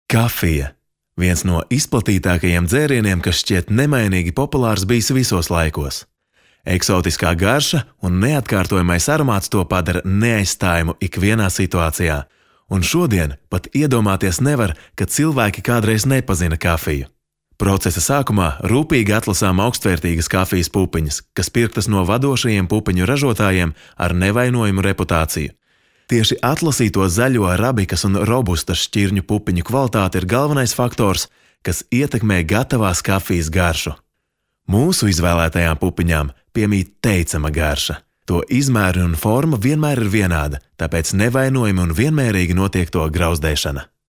Erkek Ses